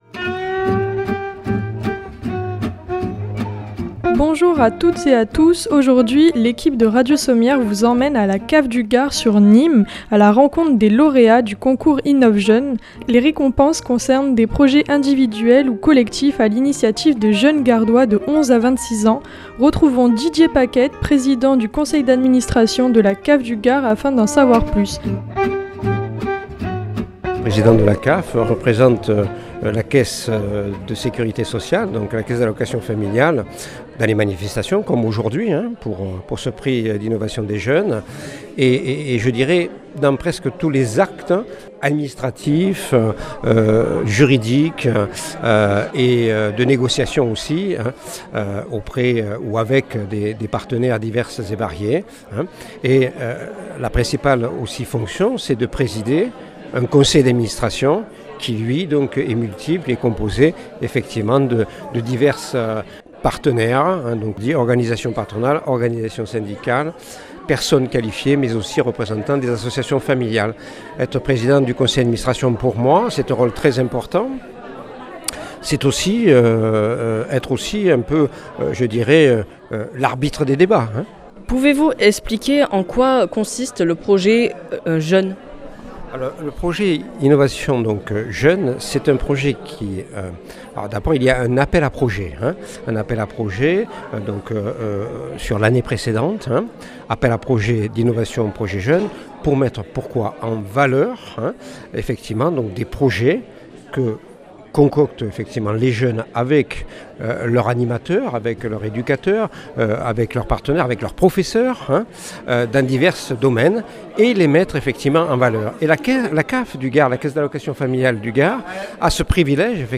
Reportage sur les lauréats des Projets Jeunes du Gard